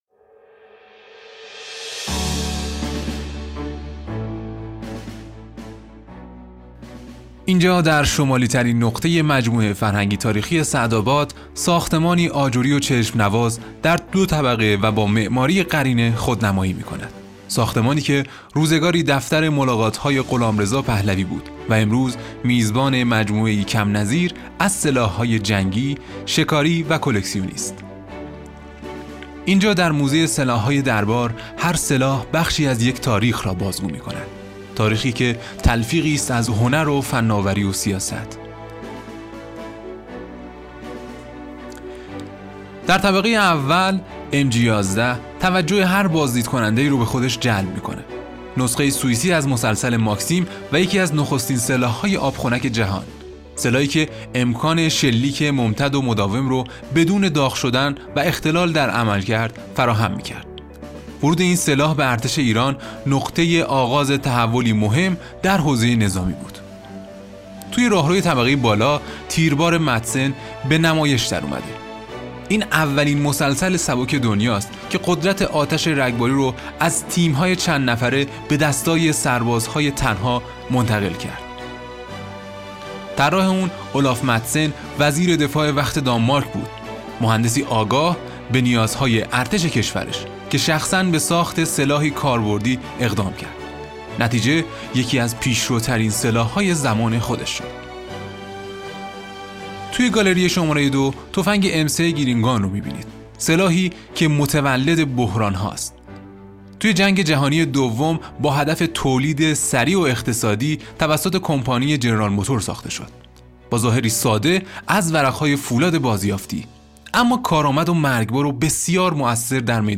راهنمای صوتی موزه سلاح‌های دربار
ارائه نوین اطلاعات آثار شاخص موزه سلاح های دربار به شکل صوتی و روایی